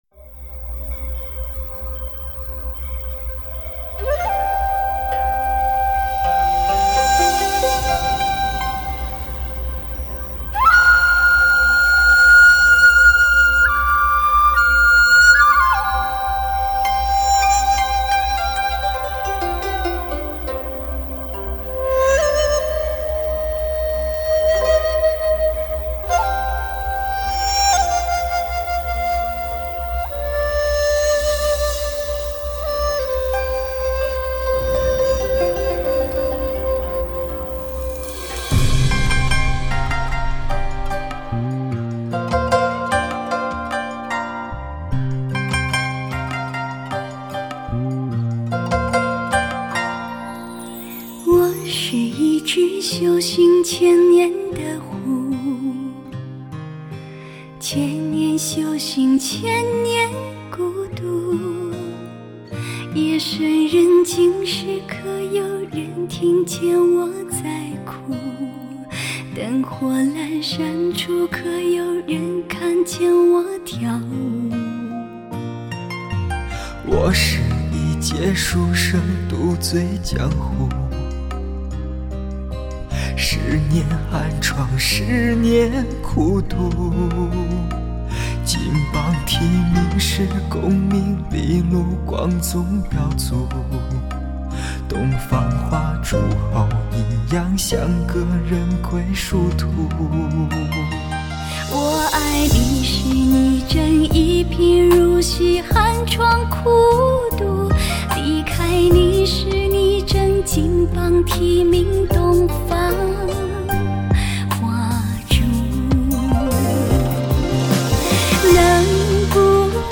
抒发心情放松的解药、抚慰心灵的疗伤音乐、内心情感世界的告白。
* 现代发烧深情代表作，首席疗伤音乐男声魅力。
本专辑延续上几张专辑忧郁纯美的曲风，在感伤的歌曲中，眼泪带走了悲伤
合唱版